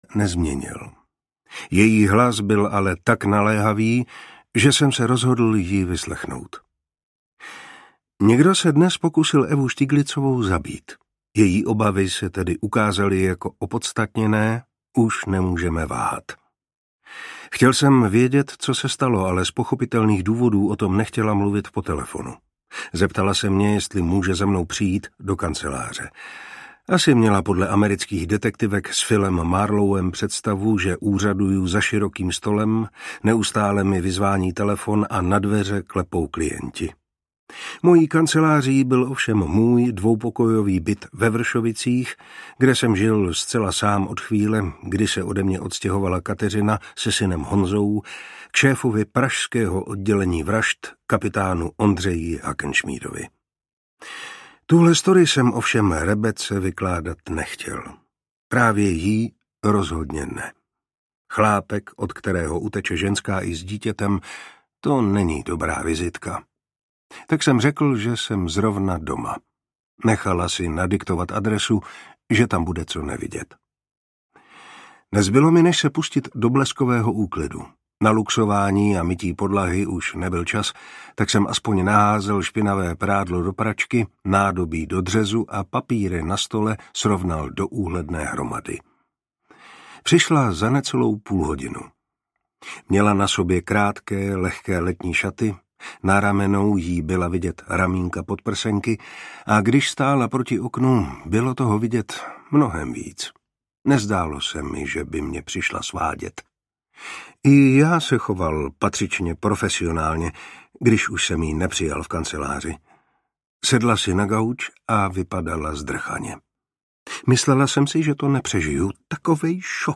Kdo zabije Evu S.? audiokniha
Ukázka z knihy
Čte Martin Preiss.
Vyrobilo studio Soundguru.
• InterpretMartin Preiss